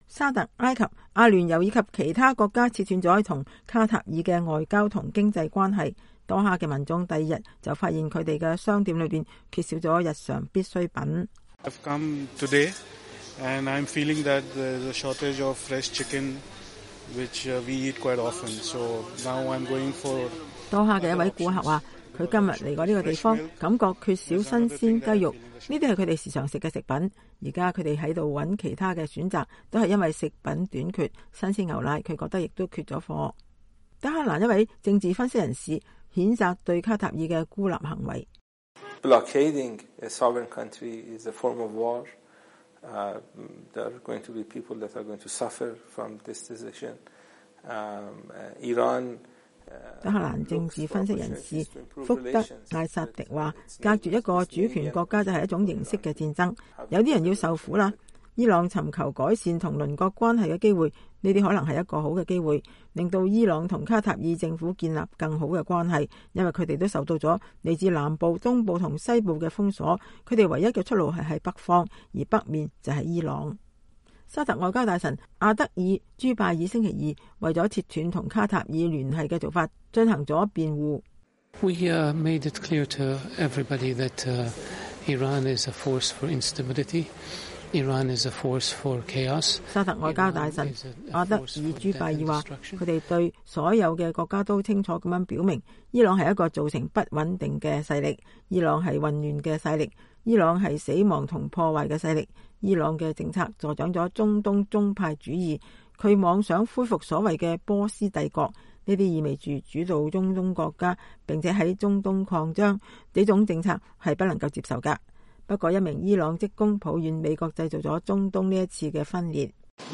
德黑蘭一位政治分析人士譴責對卡塔爾的孤立行為。